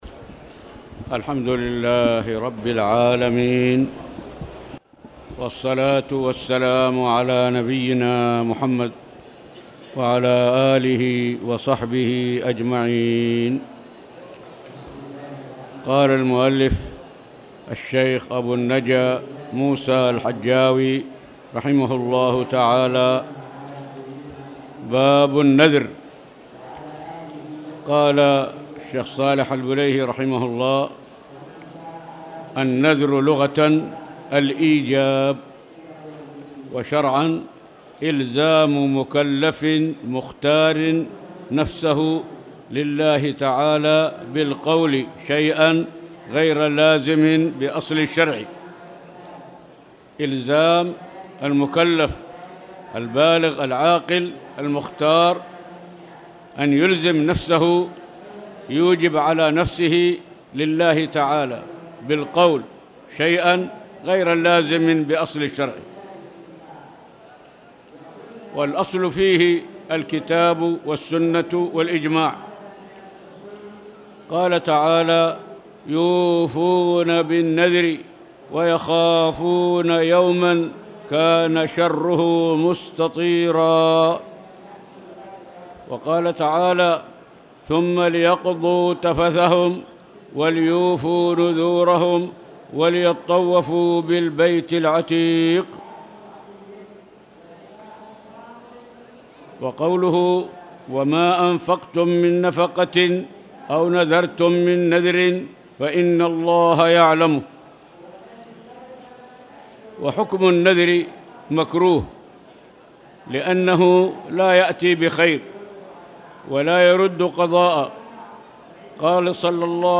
تاريخ النشر ١٤ ربيع الأول ١٤٣٨ هـ المكان: المسجد الحرام الشيخ